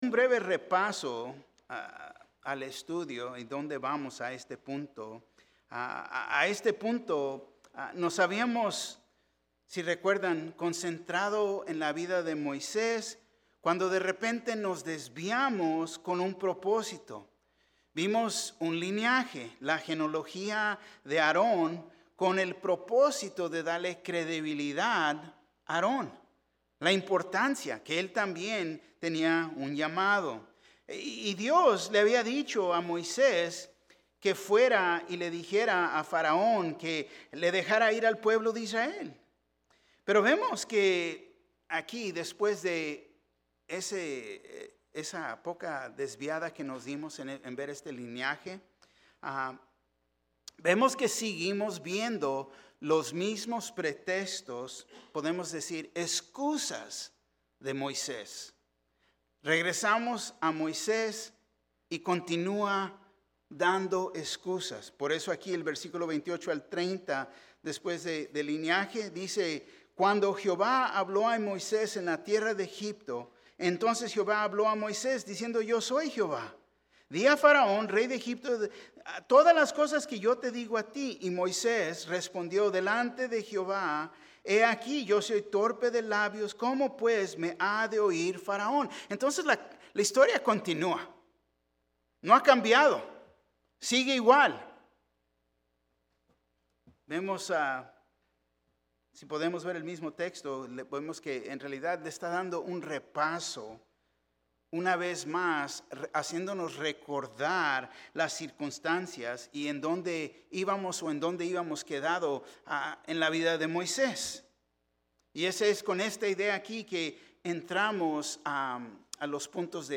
Un mensaje de la serie "Liberados." Cuando Dios nos llama, y Él nos envía; Él nos ha dado todo don necesario para cumplir Su llamado.